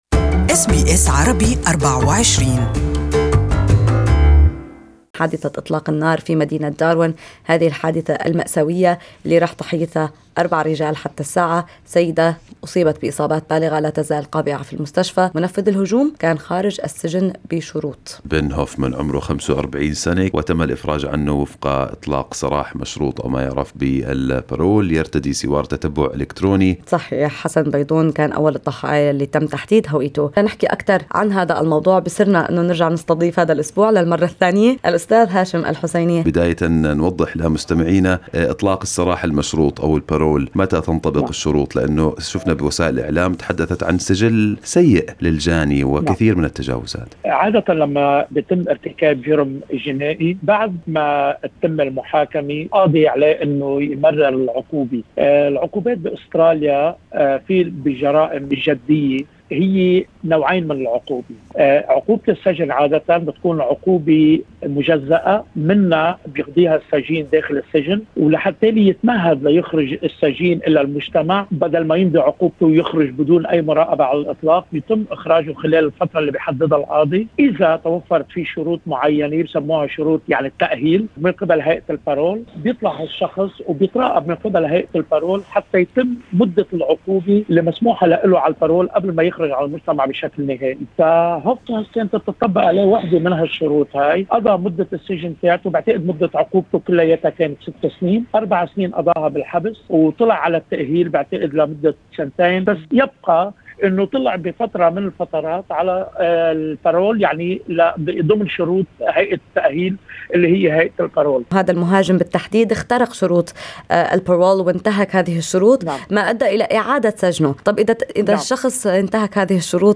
المحامي